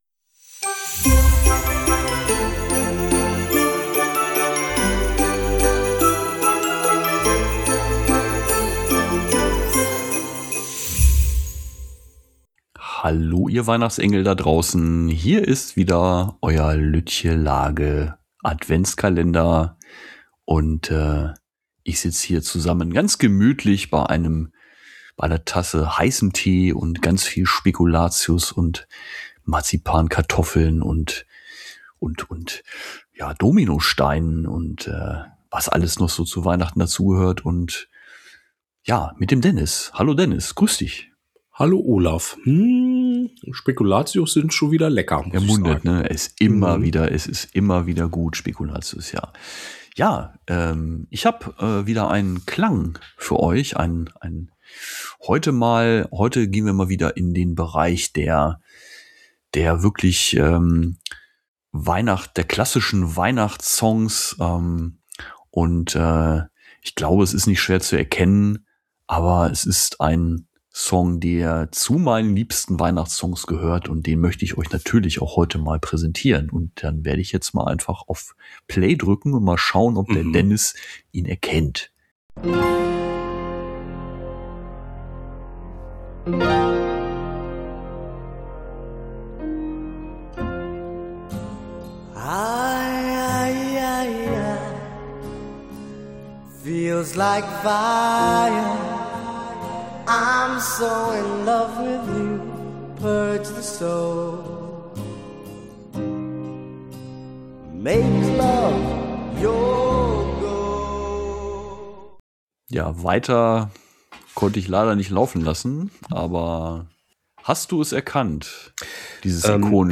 Nostalgie-Sound zum Miträtseln.